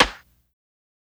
SNARE_QUEENS.wav